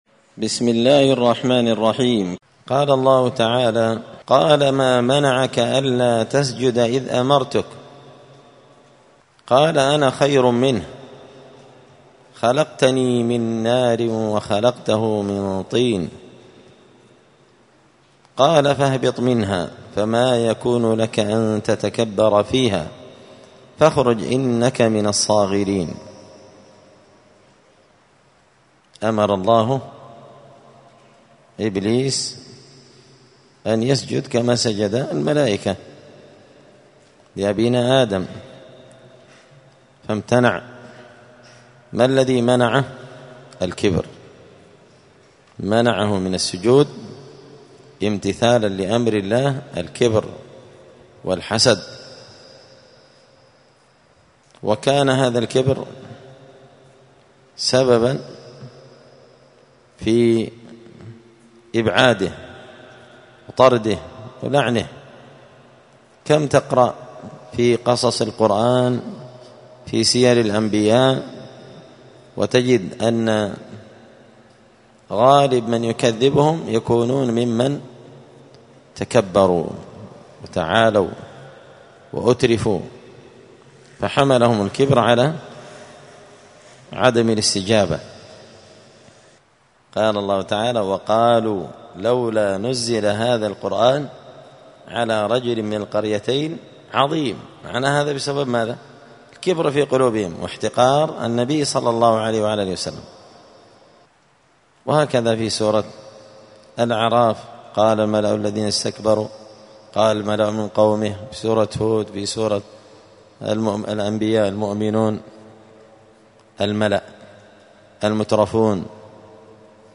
📌الدروس اليومية
دار الحديث السلفية بمسجد الفرقان بقشن المهرة اليمن